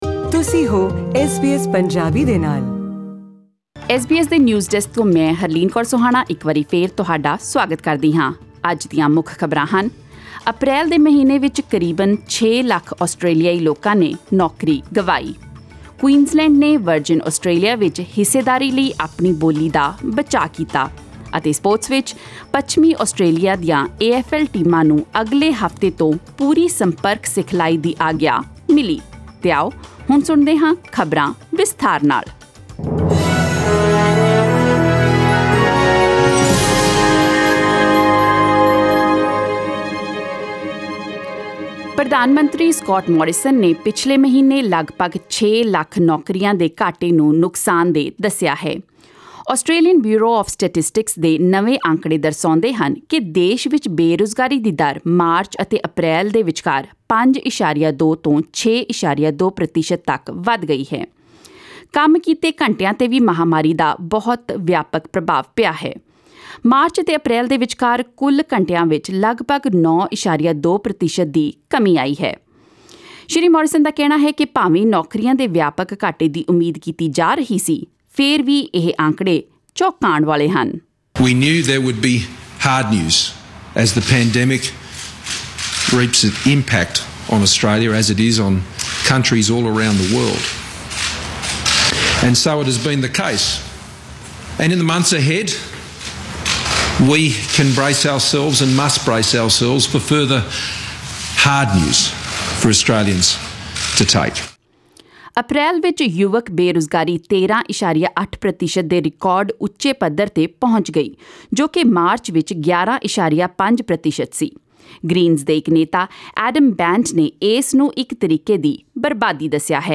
Australian News in Punjabi: 14 May 2020